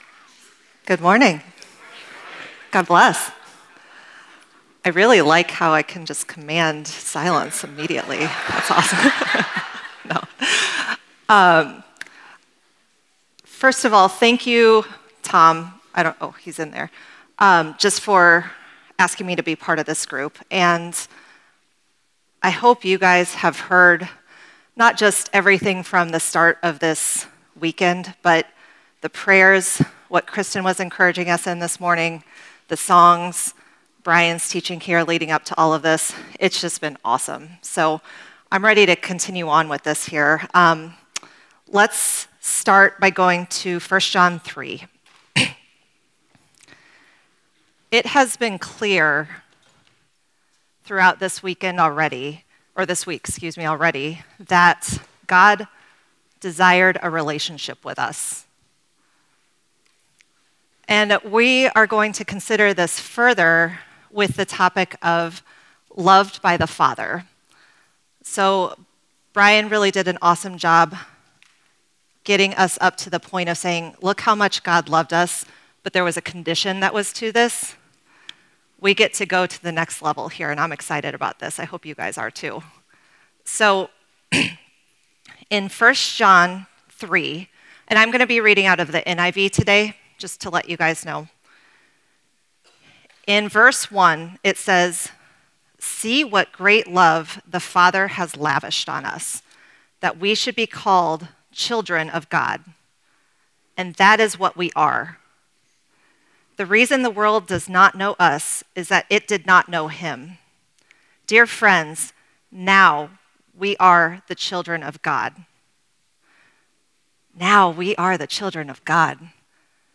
What Is Man? (Family Camp 2025) – Part 6